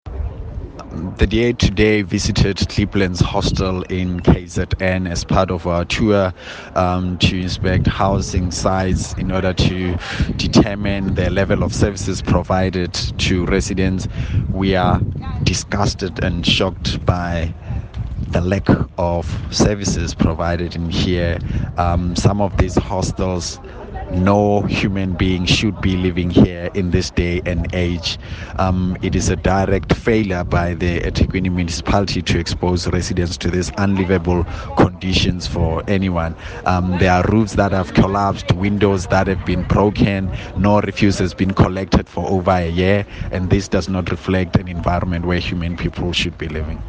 Issued by Solly Malatsi MP – DA Shadow Minister of Human Settlements